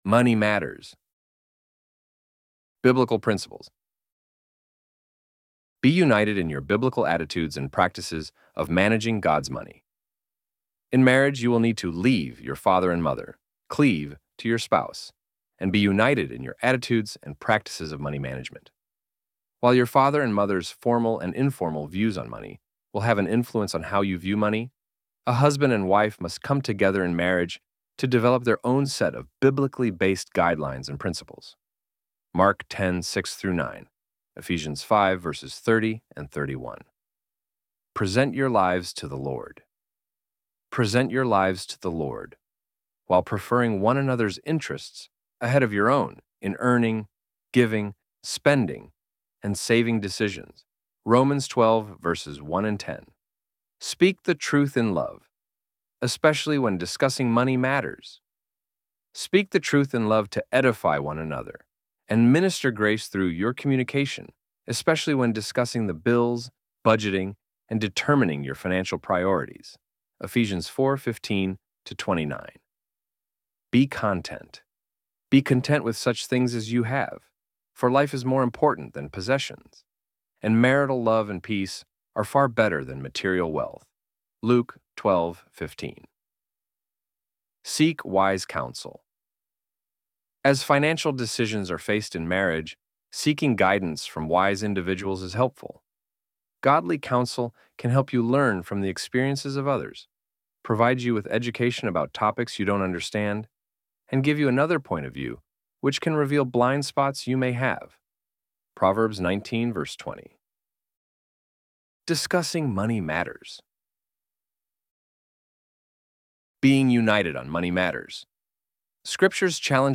ElevenLabs_Money_Matters_2025.pdf.mp3